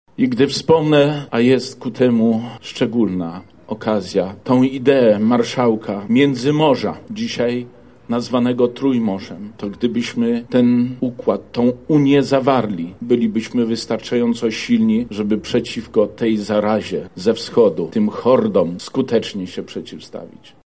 W tym roku odbyły się na placu Litewskim, gdzie pod pomnikiem marszałka złożono również kwiaty.
• mówi Zbigniew Wojciechowski, wicemarszałek województwa lubelskiego.